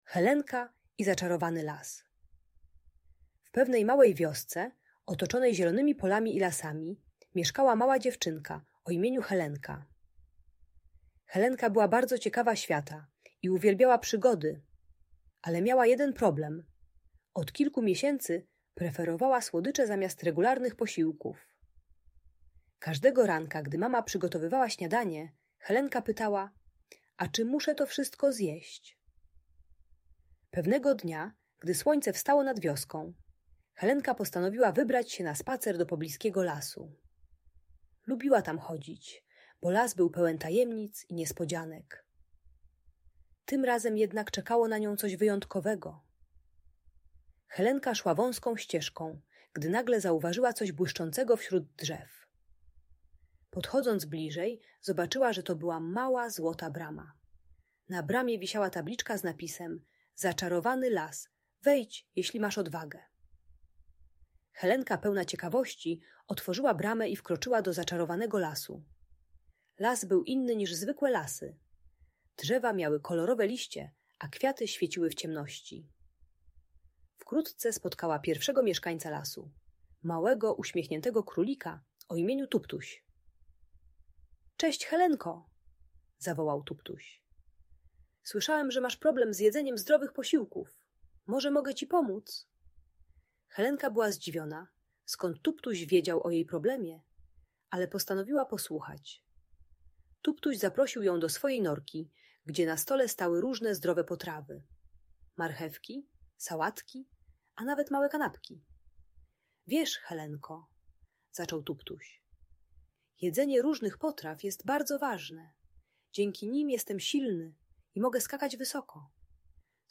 Helenka i Zaczarowany Las - Audiobajka